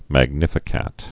(măg-nĭfĭ-kăt)